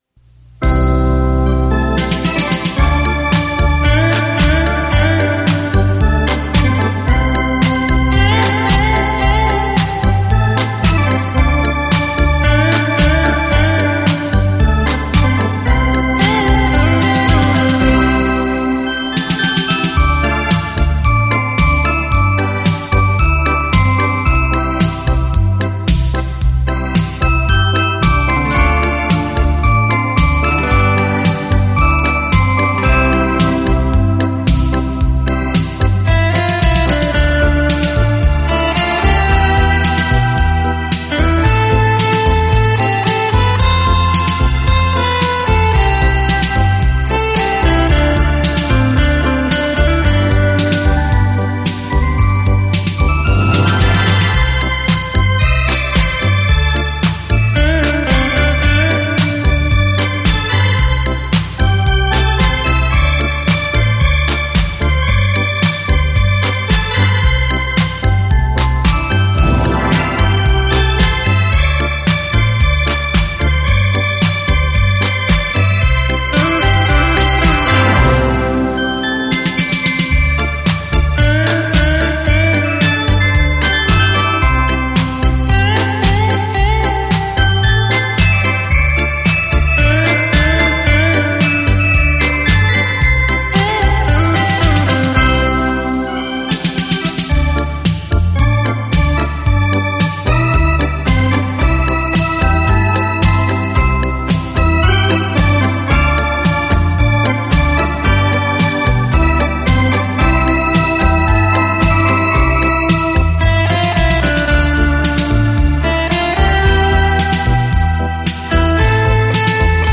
熟悉的旋律  优美的音乐  勾起我们绵绵依旧之情......